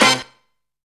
SWEATY HIT.wav